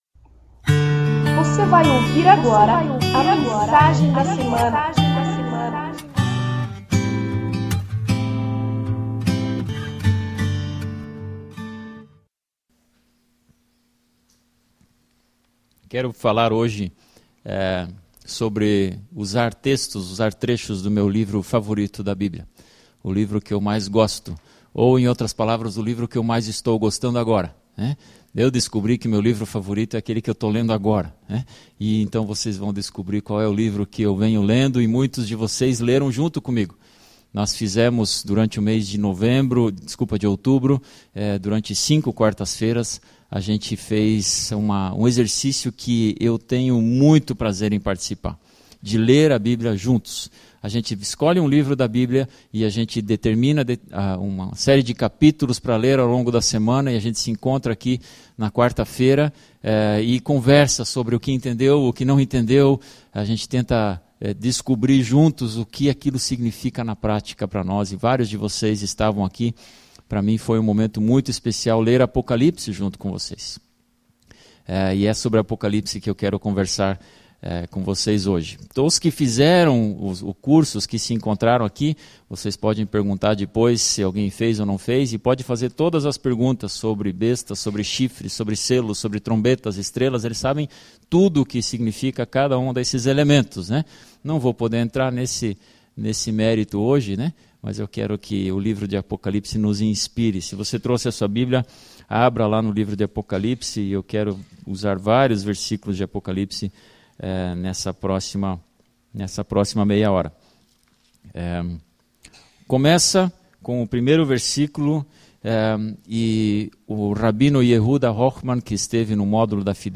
Ouça esta mensagem e que Deus te abençoe!